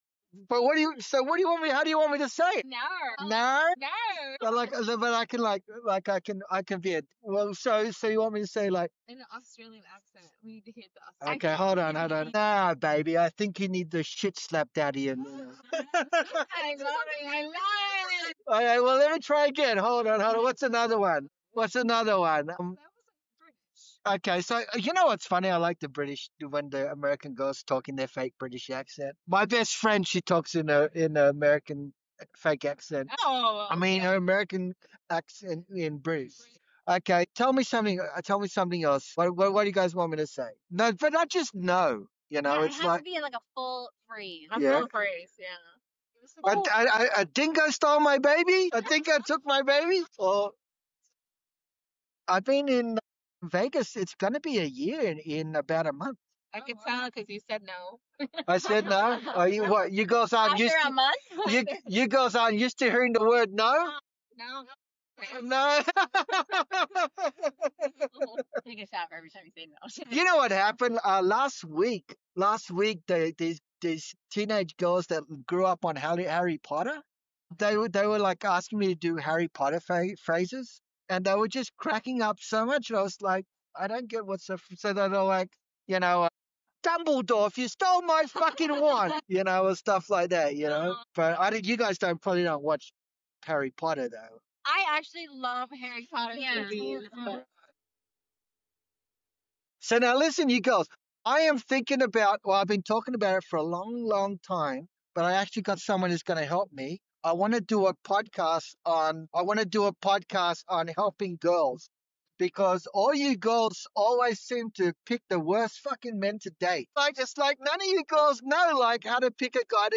Join me for today’s ride—a candid, free-flowing conversation that naturally unfolds into an honest discussion about intimacy and communication with your partner.
Tune in and enjoy the laughter, insights, and genuine banter.